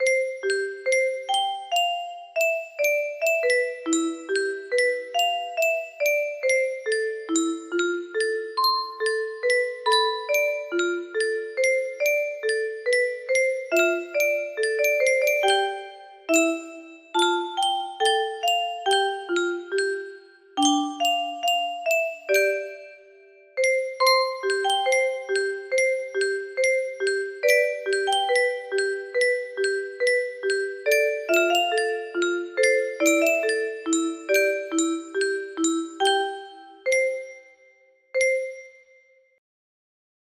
Unknown Song - Unknown Artist music box melody